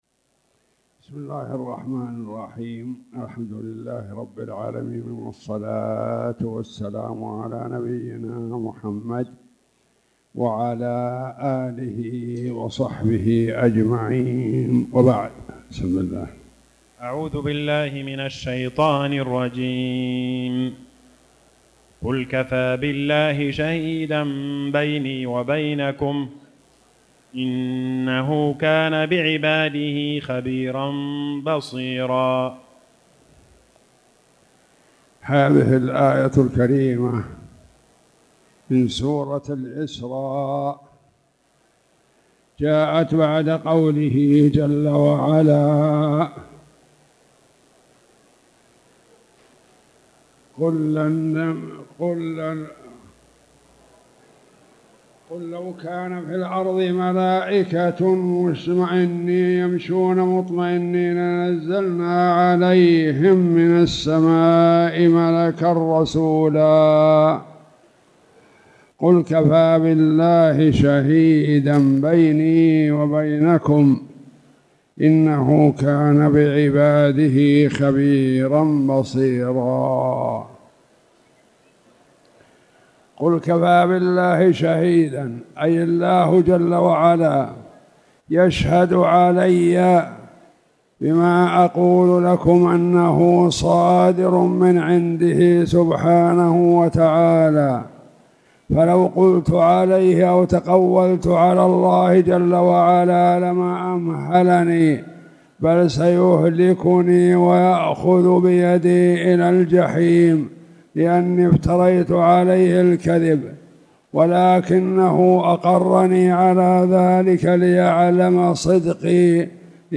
تاريخ النشر ٢٣ صفر ١٤٣٨ هـ المكان: المسجد الحرام الشيخ